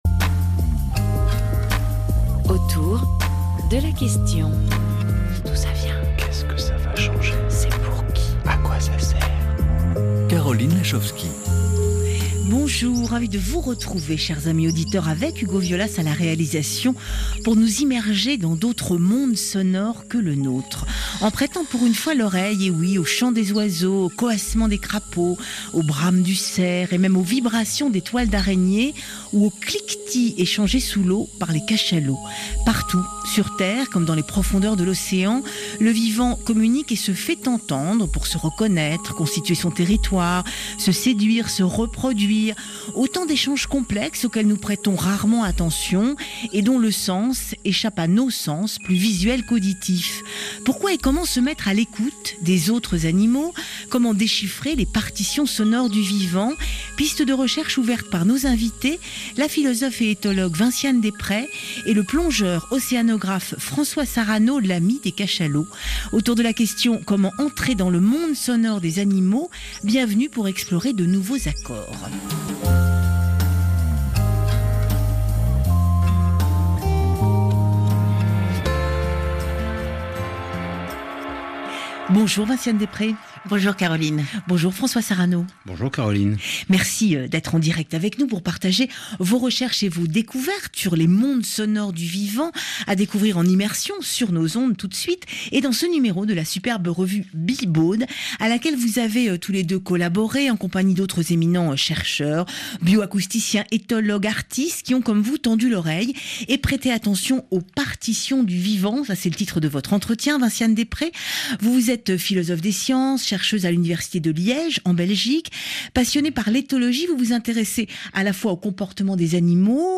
Des questions passionnantes et des réponses dans l’ émission RFI “autour de la question ” :